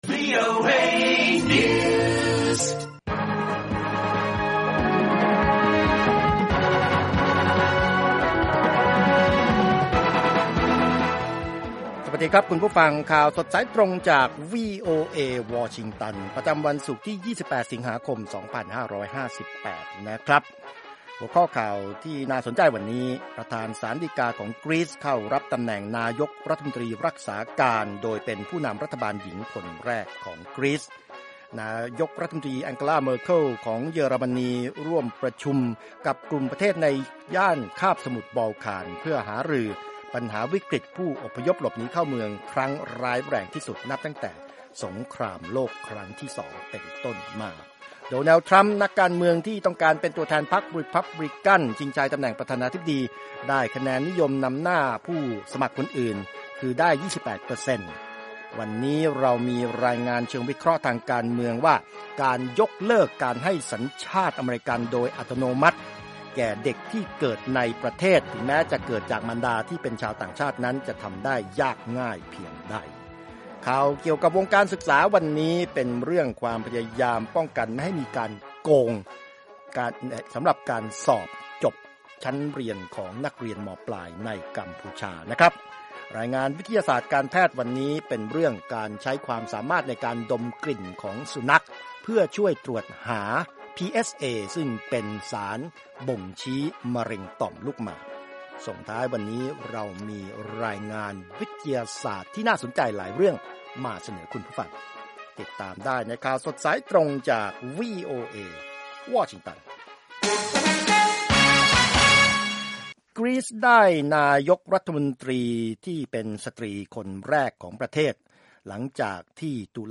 ข่าวสดสายตรงจากวีโอเอ ภาคภาษาไทย 6:30 – 7:00 น. วันศุกร์ 28 ส.ค. 2558